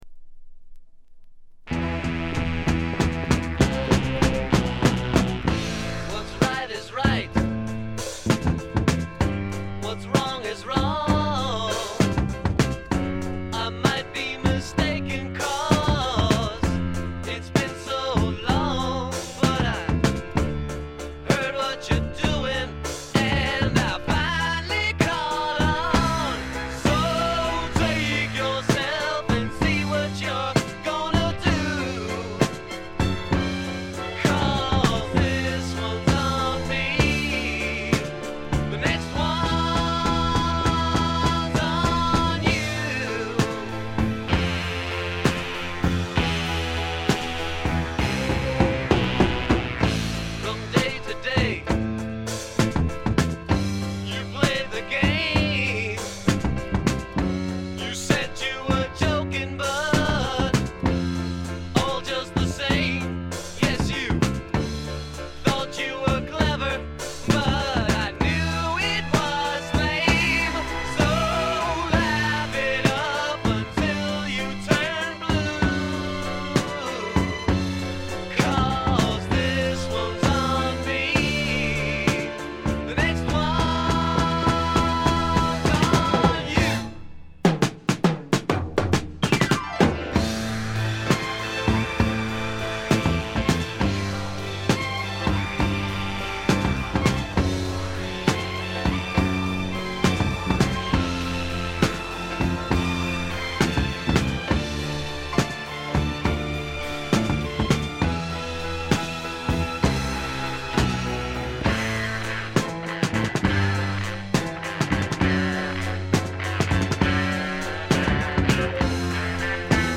ほとんどノイズ感無し。
パワー・ポップ、ニッチ・ポップ好きにもばっちりはまる傑作です。
試聴曲は現品からの取り込み音源です。
Lead Vocals, Guitar, Piano